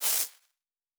Sci-Fi Sounds / Electric / Spark 08.wav
Spark 08.wav